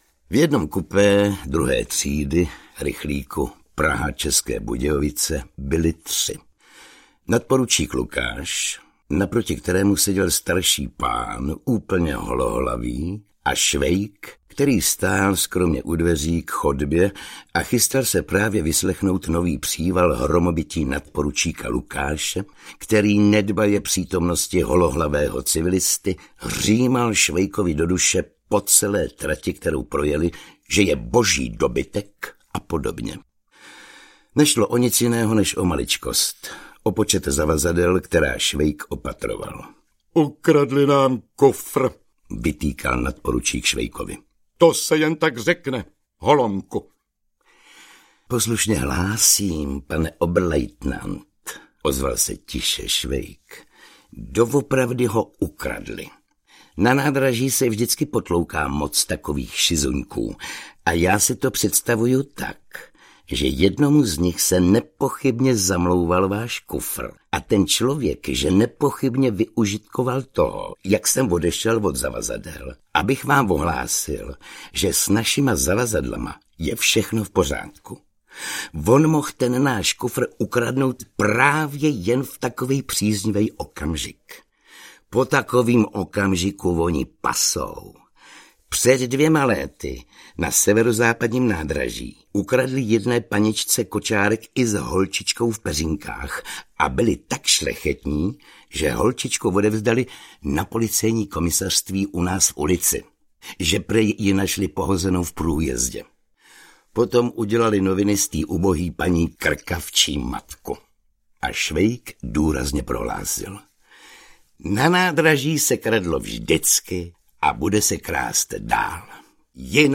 Osudy dobrého vojáka Švejka za světové války 2 audiokniha
Pokračování úspěšné audioverze jedné z nejoblíbenějších českých knih. Kompletní znění II. dílu Na frontě v podání Oldřicha Kaisera.
Ukázka z knihy
• InterpretOldřich Kaiser